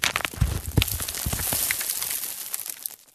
dirt.ogg